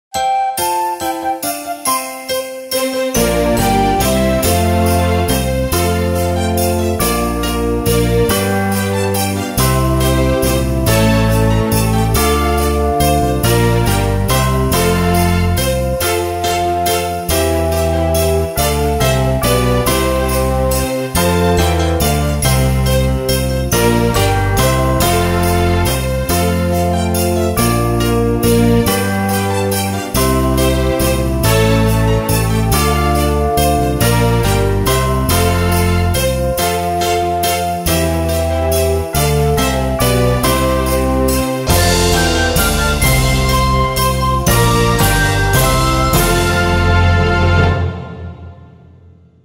I Think He Was Really Murdered Instrumental